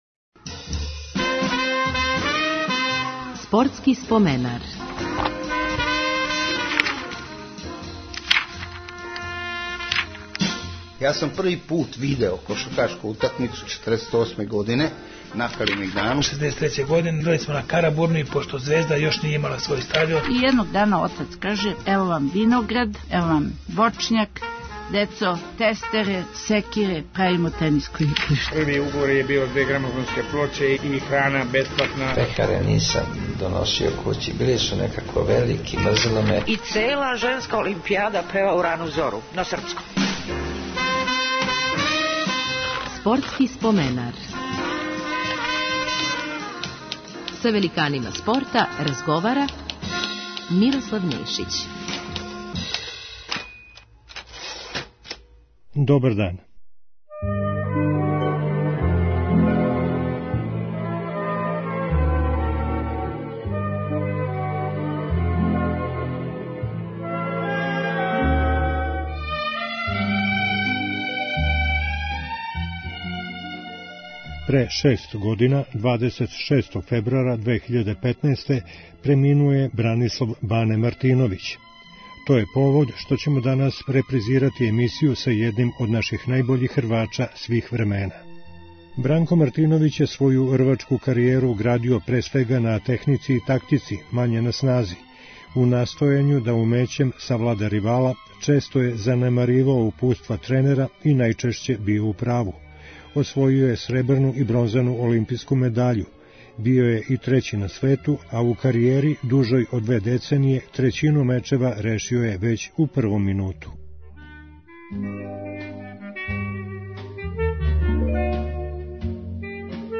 То је повод што ћемо репризирати емисију којој нам је био гост 6. марта 2011.